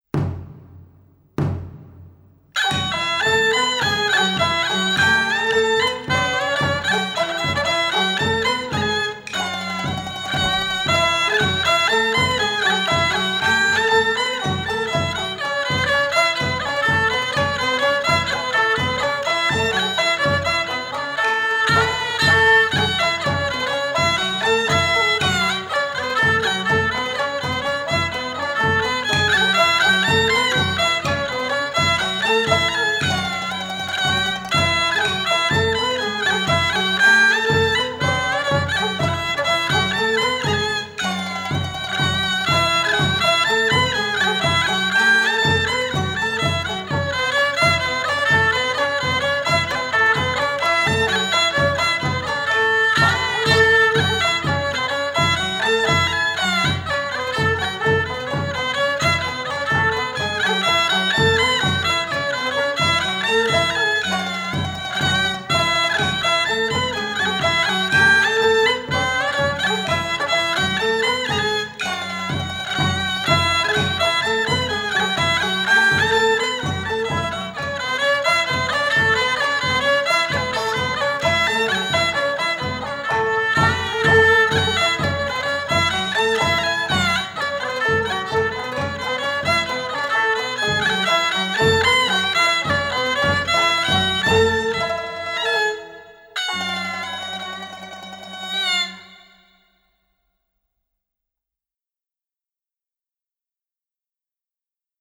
0012-京胡名曲朝天子.mp3